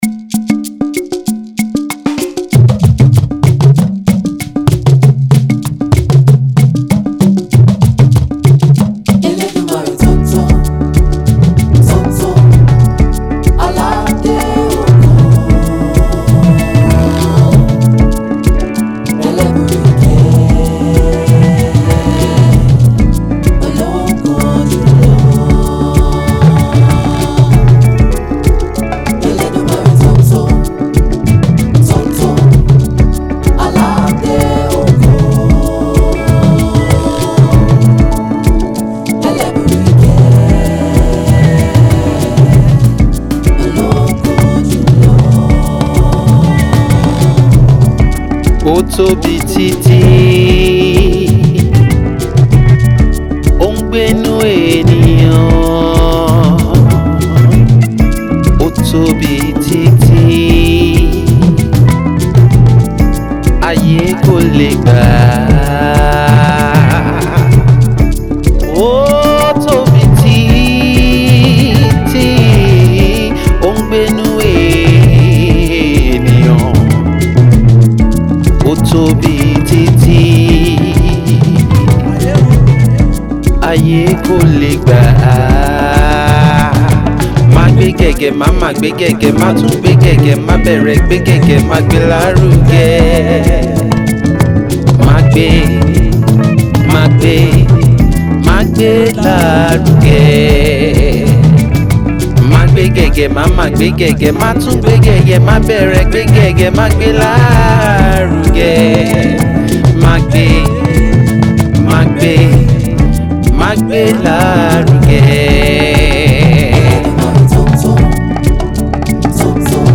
Popular gospel artiste
a Yoruba song
With the chorus running at the background